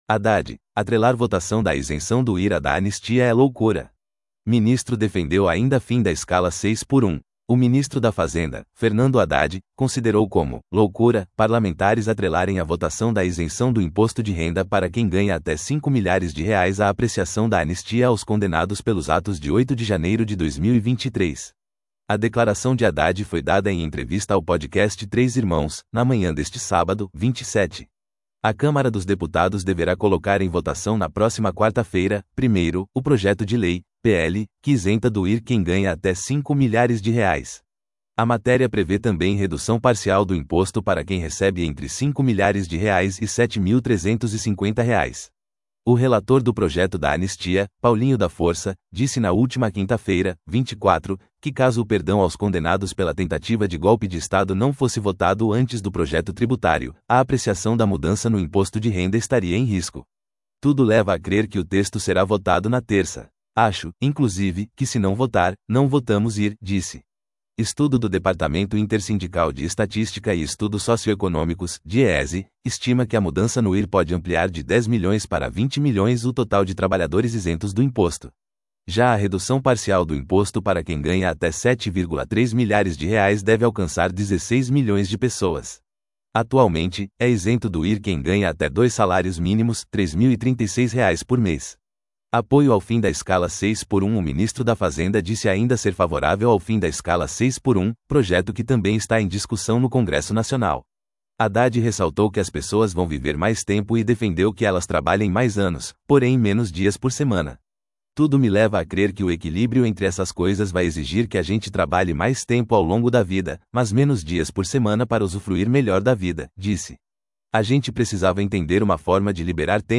A declaração de Haddad foi dada em entrevista ao Podcast 3 Irmãos, na manhã deste sábado (27).